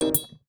UIClick_Smooth Tone Metallic Double Hit 03.wav